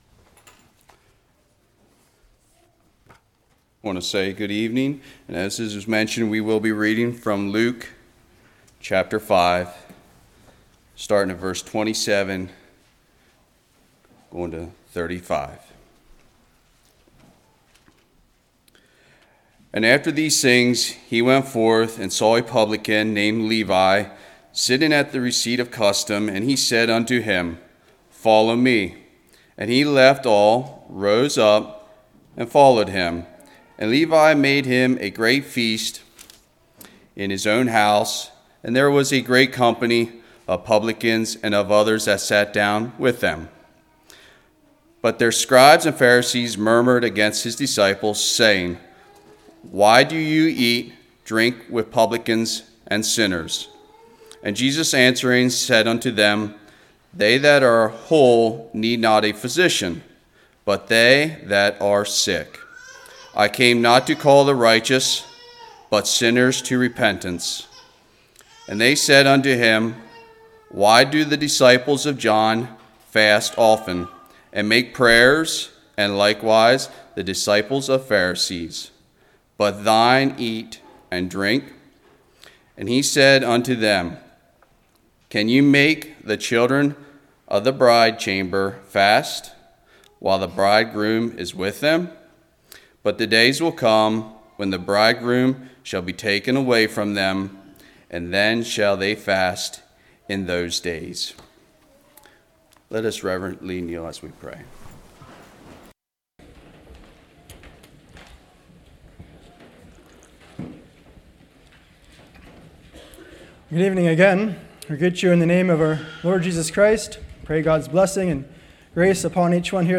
Luke 5:27-35 Service Type: Revival What is joy?